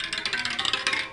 sounds_bicycle_spokes.ogg